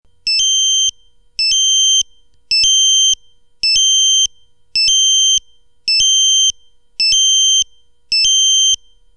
Vollalarm.mp3